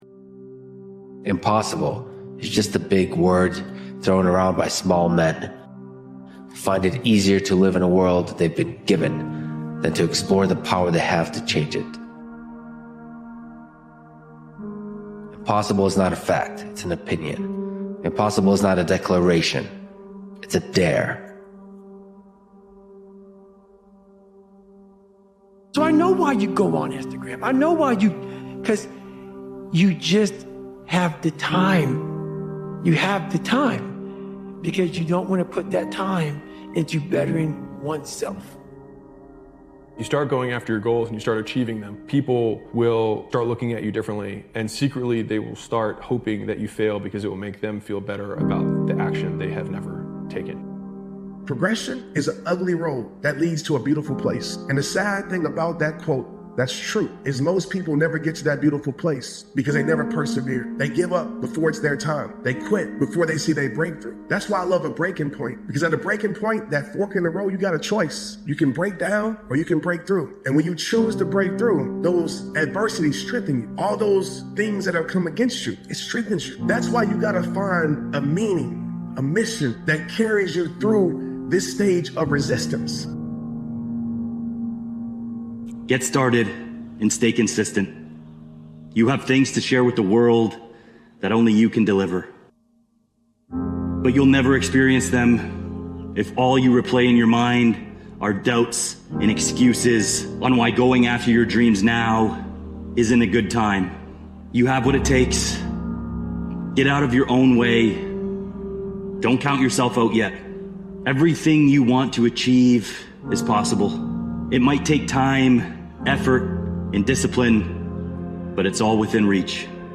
Headliner Embed Embed code See more options Share Facebook X Subscribe You'll learn how to confront your inner struggles and overcome personal challenges through compelling speeches. Discover the strength needed to battle your limitations and transform yourself from within.